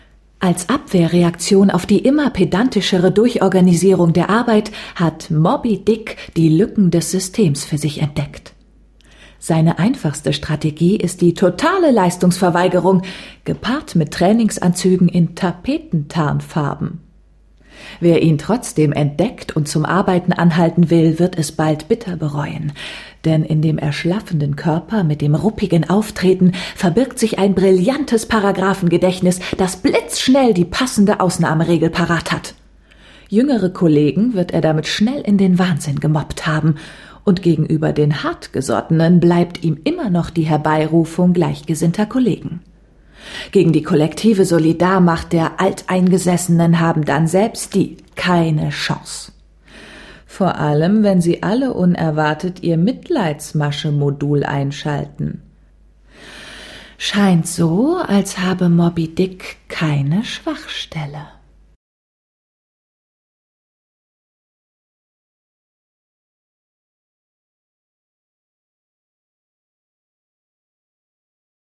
Sprecherin und Schauspielerin. Synchronsprecherin, Studiosprecherin
Sprechprobe: Sonstiges (Muttersprache):
female german voice over talent